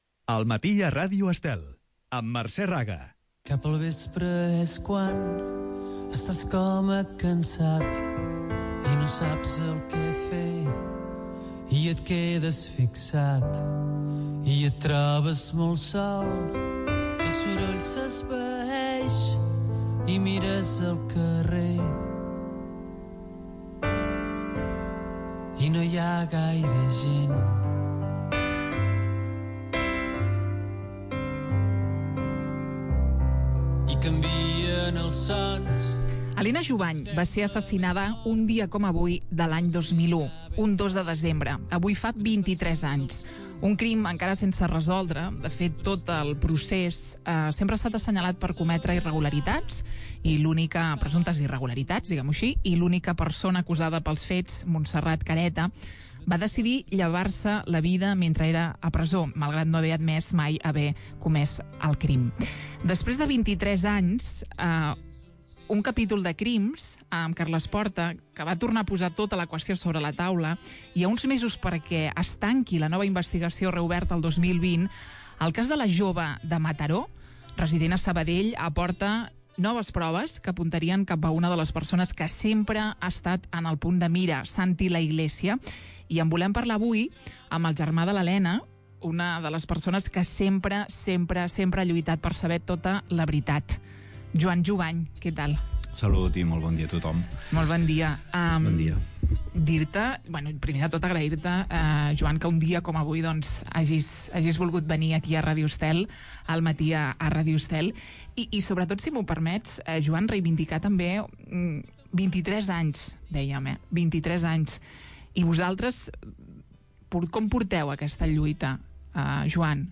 En aquesta entrevista a El Matí a Ràdio Estel, revisem la totalitat d'aquestes noves proves que hem conegut a principis d'aquest mes de novembre.